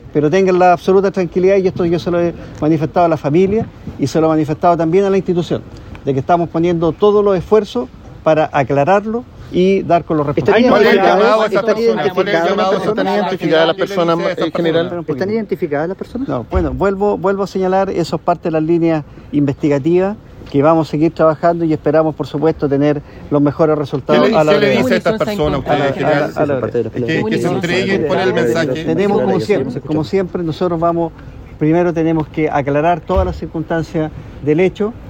Tras la cita, el jefe de la policía uniformada informó sobre el estado de salud de la víctima, agregando que llegó también para apoyar al personal de la zona y evitando confirmar si hay personas identificadas.